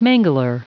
Prononciation du mot mangler en anglais (fichier audio)
Prononciation du mot : mangler